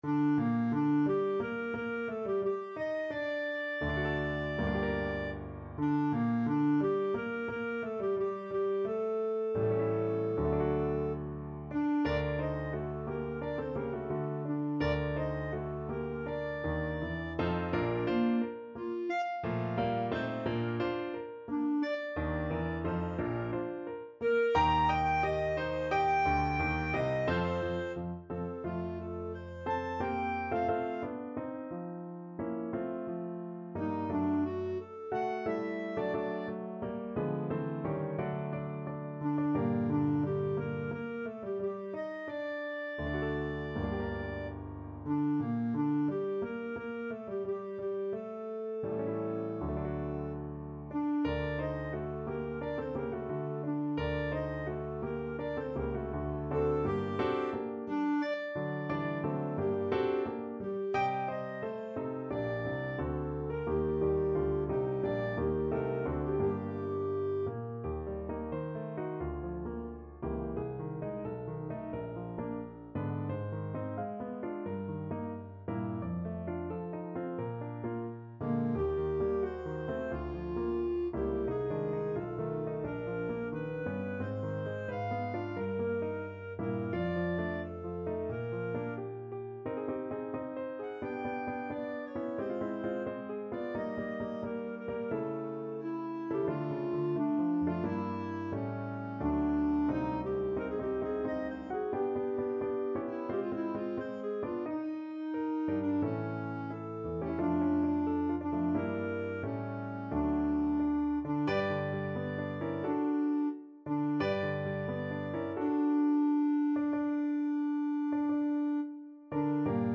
=88 Nicht schnell =100